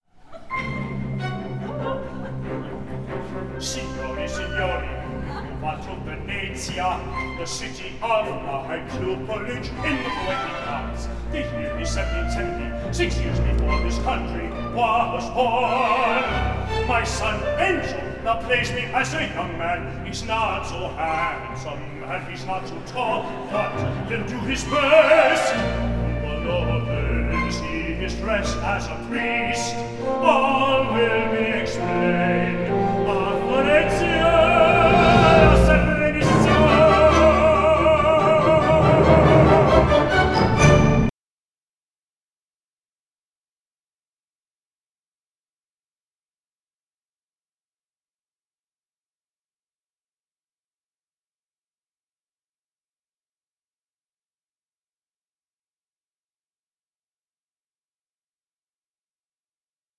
Recitativo secco e a parte